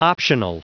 Prononciation du mot optional en anglais (fichier audio)
Prononciation du mot : optional